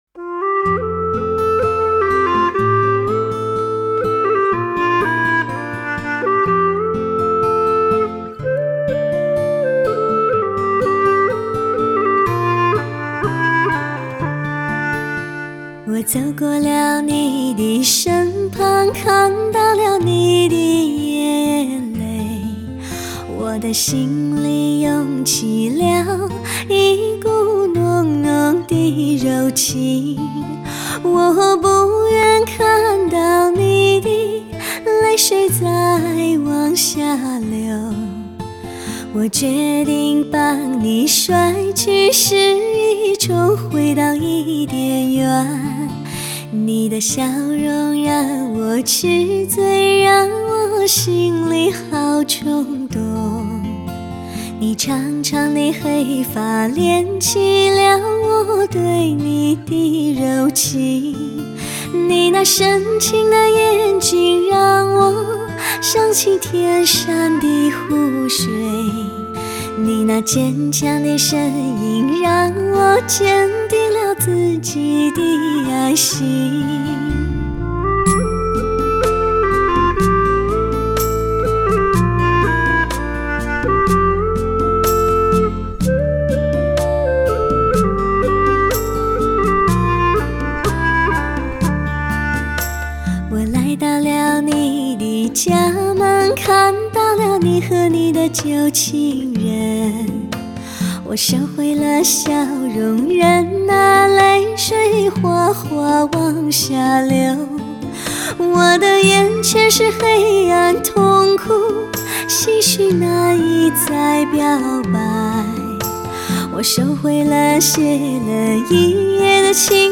纯原女声呈现能够触摸到的真实感，闻得到的清香，看得见的新鲜。